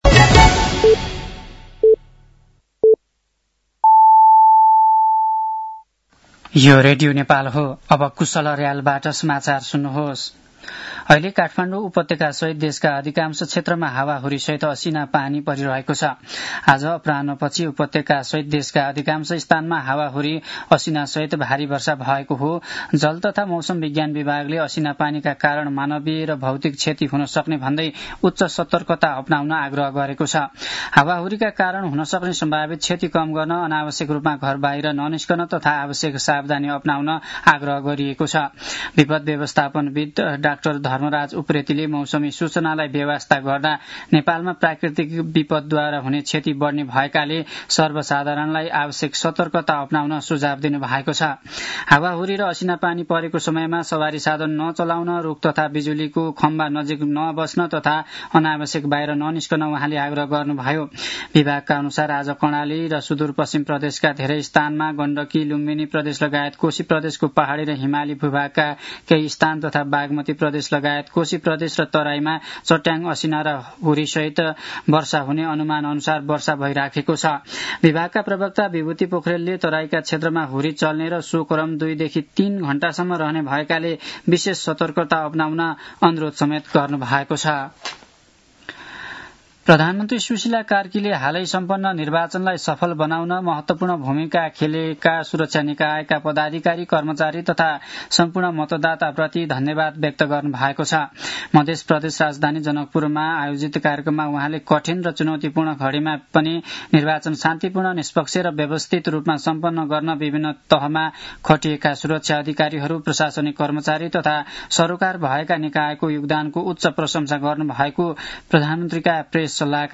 साँझ ५ बजेको नेपाली समाचार : ६ चैत , २०८२
5-pm-nepali-news-12-06.mp3